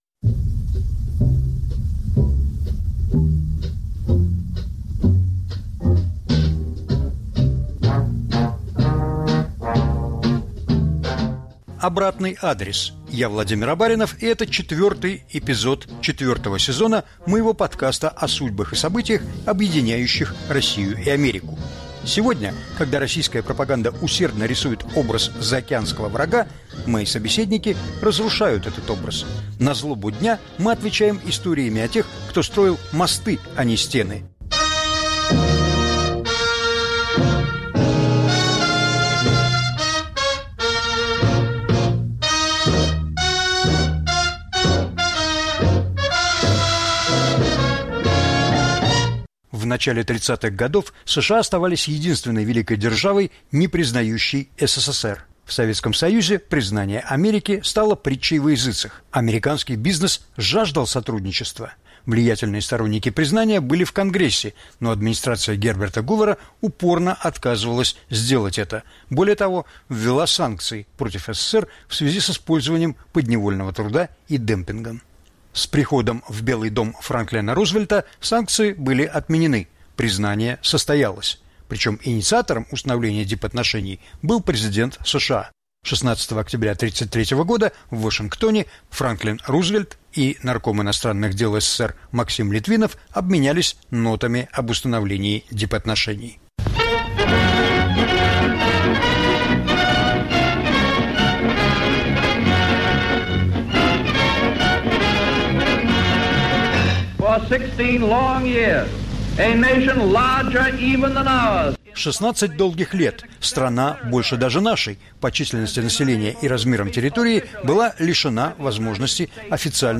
увлекательная беседа